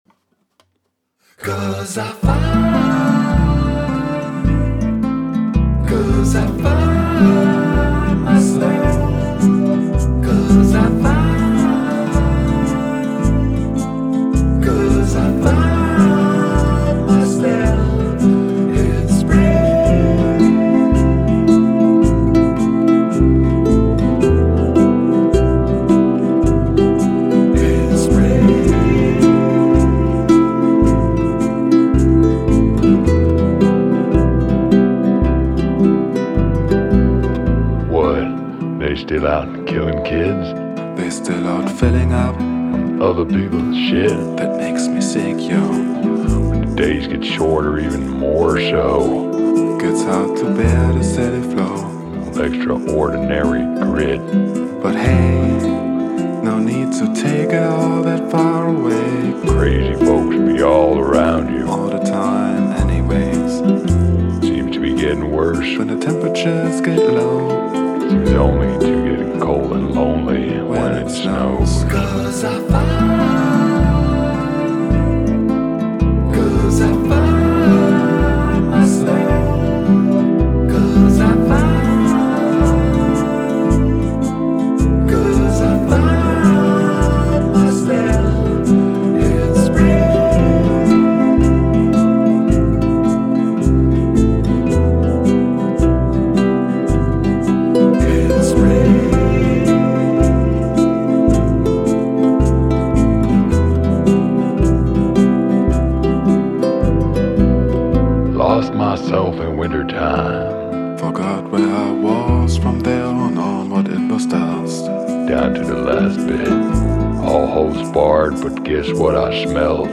alternative art collaboration downtempo dreamy dusty
easy electronica experimental
leftfield
thus spawning a classic duet in a highly modern style